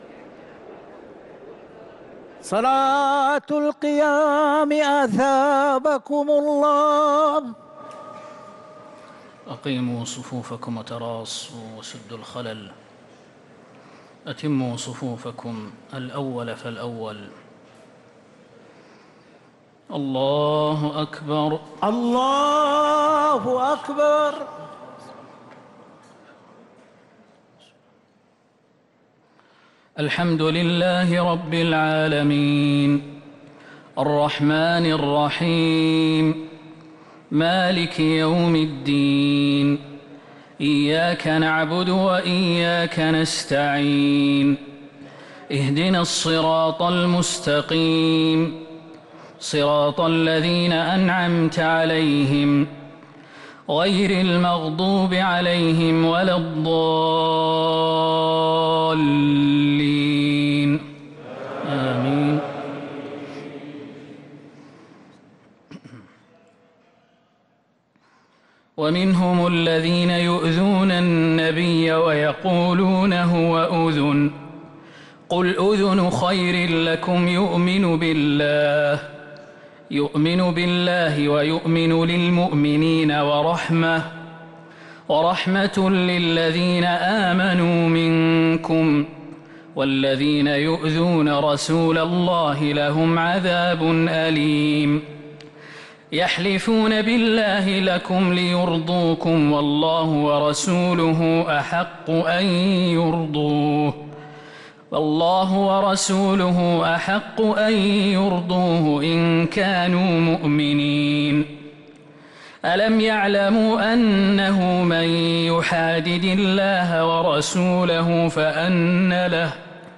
صلاة التراويح ليلة 14 رمضان 1444 للقارئ خالد المهنا - الثلاث التسليمات الأولى صلاة التراويح